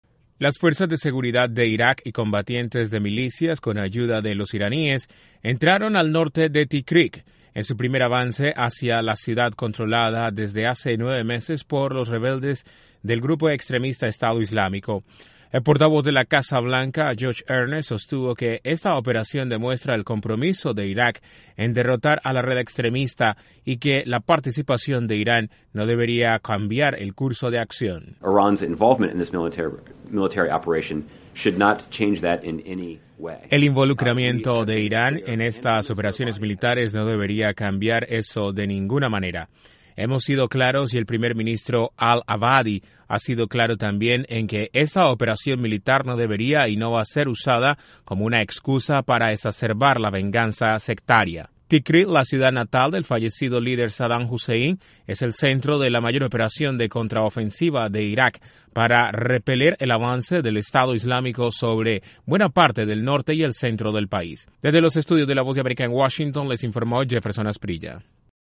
La Casa Blanca señaló que el involucramiento de Irán en la retoma del control en la ciudad de Tikrit en Irak en la guerra contra el autodenominado Estado Islámico no debería cambiar la dinámica. Desde la Voz de América en Washington DC informa